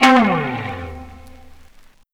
Track 15 - Guitar Slide 01.wav